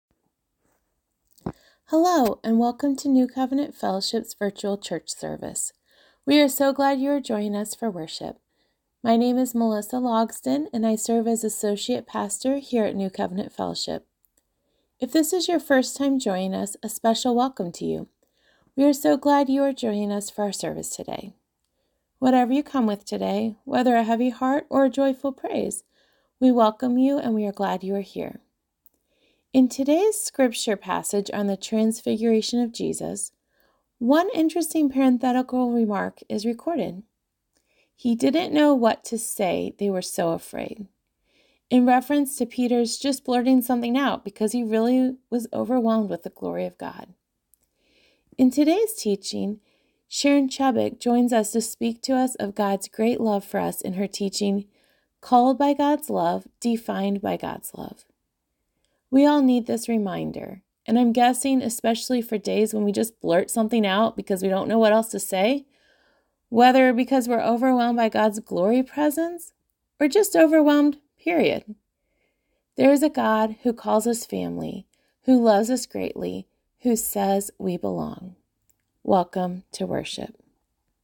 Welcome-2.11.wav